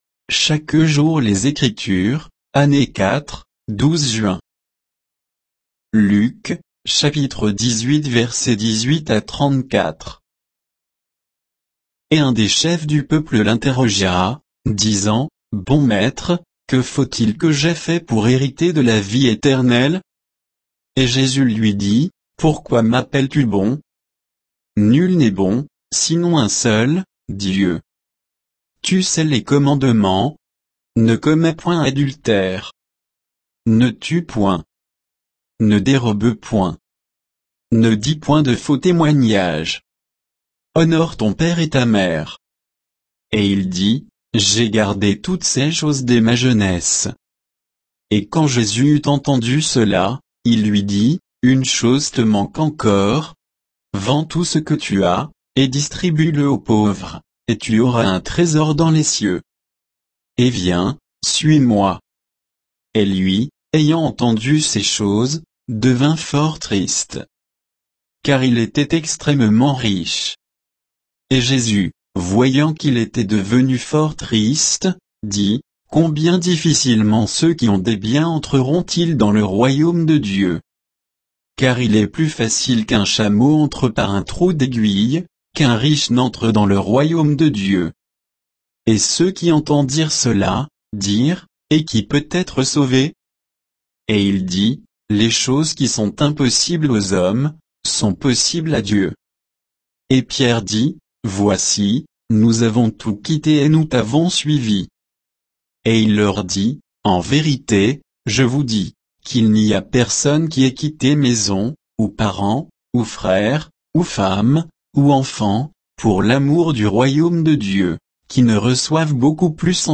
Méditation quoditienne de Chaque jour les Écritures sur Luc 18